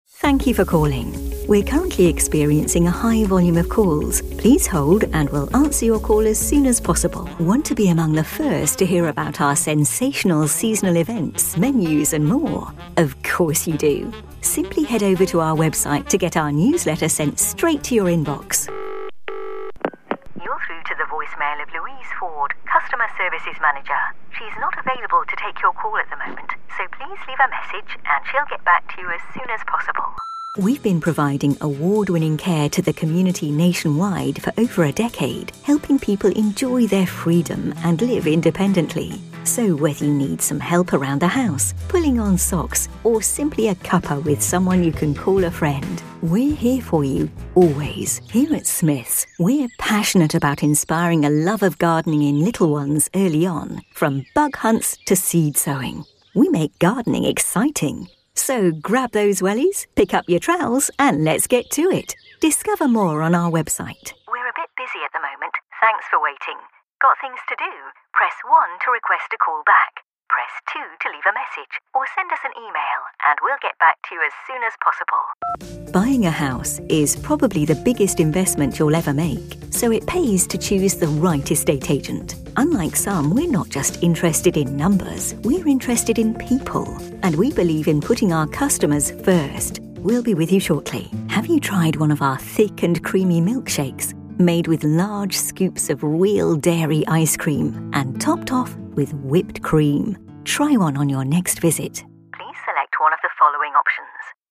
Inglês (britânico)
Microfone: Neumann TLM103
Cabine de locução: VocalBooth